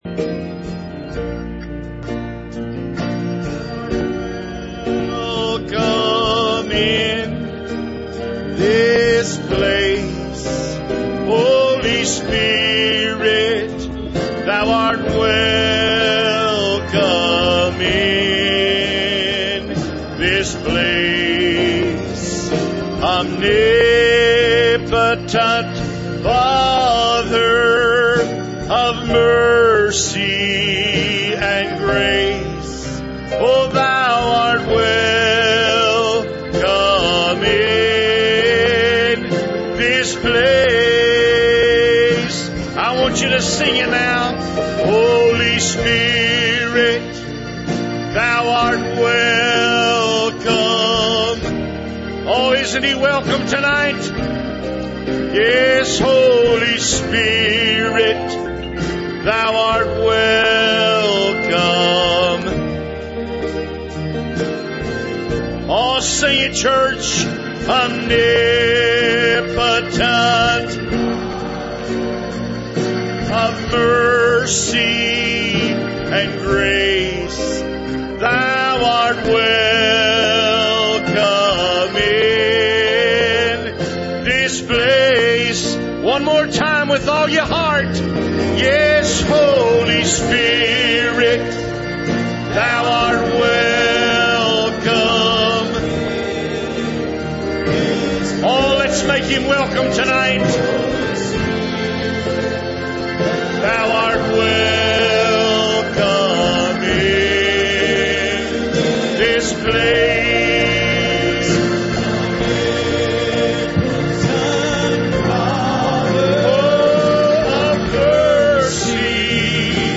Index of /Sermons/2015/05